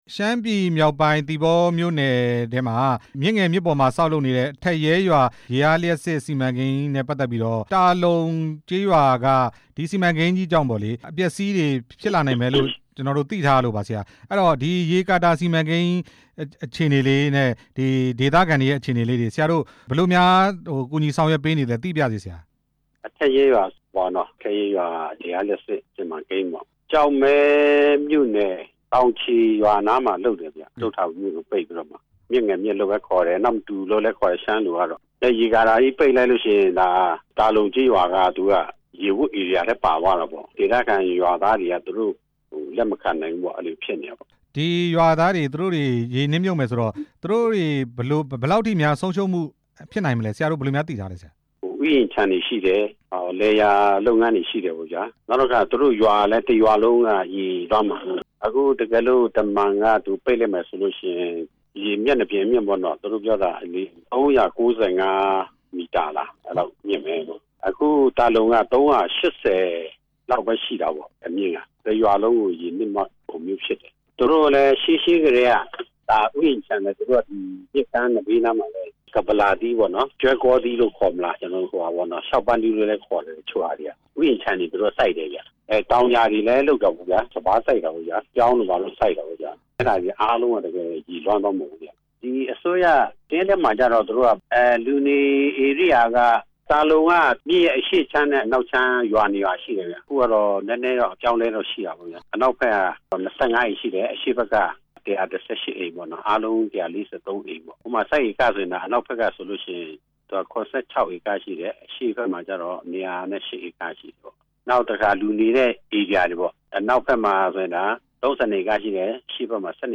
သီပေါ လွှတ်တော်ကိုယ်စားလှယ် ဦးစိုင်းခမ်းအောင်နဲ့ ဆက်သွယ်မေးမြန်းချက်